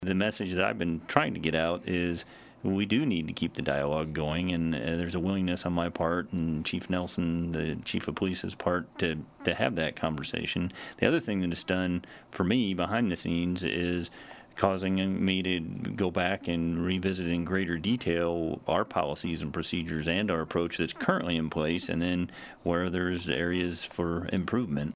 Schrage was a guest on the KSAL Morning News Extra and says area leaders can’t just point to a map and say this didn’t happen in Salina and ignore it.